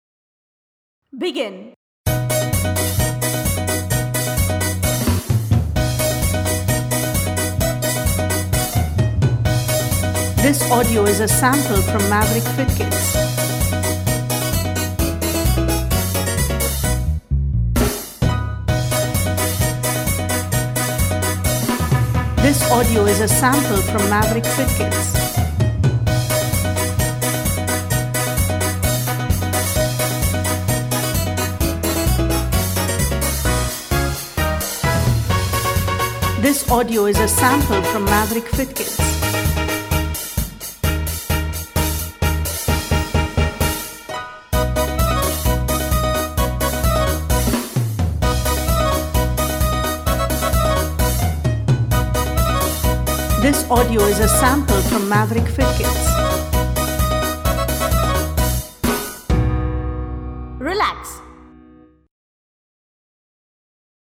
* Use the audio below to maintain a rhythm: